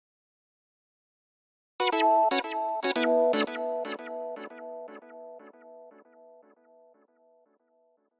11 Solo Synth PT2.wav